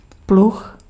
Ääntäminen
Synonyymit araire brabant gratte déneigeuse Ääntäminen France: IPA: [ʃa.ʁy] Tuntematon aksentti: IPA: /ʃaʀy/ Haettu sana löytyi näillä lähdekielillä: ranska Käännös Ääninäyte Substantiivit 1. pluh {m} Suku: f .